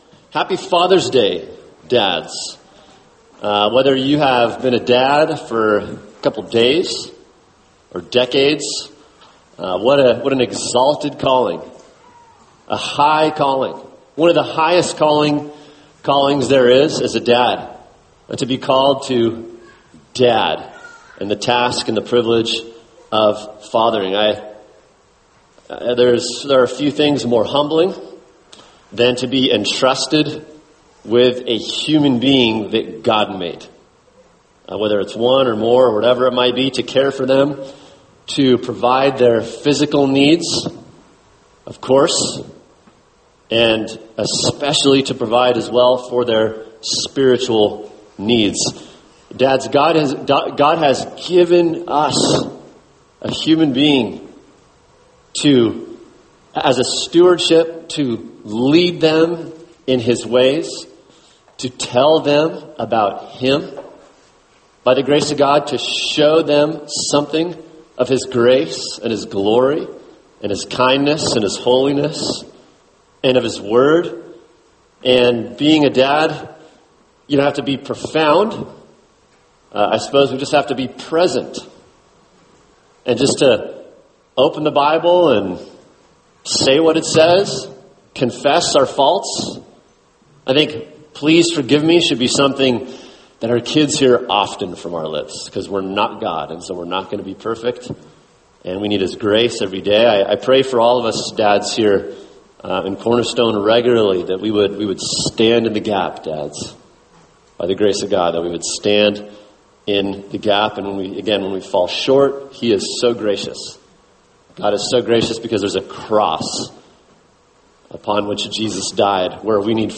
[sermon] Matthew 24:15-26 The End Times: The Person and Work of the Antichrist | Cornerstone Church - Jackson Hole